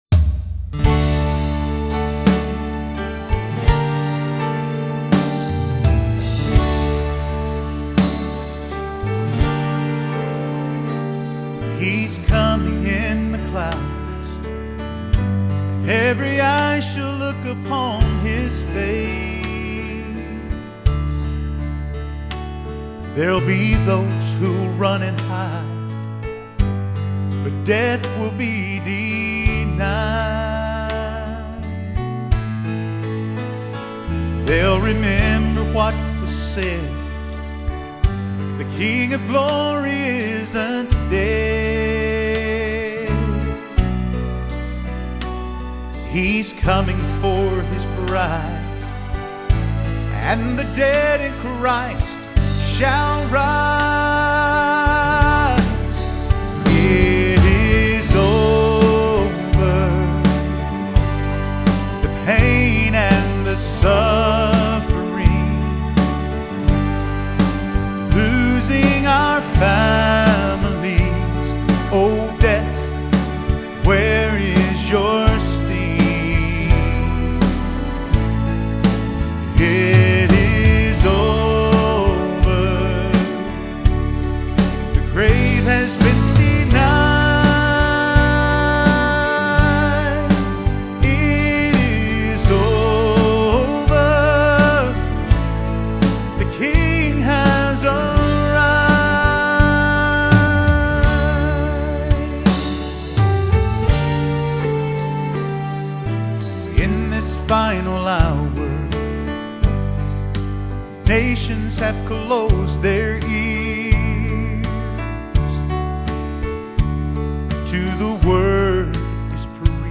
Southern Gospel Songwriter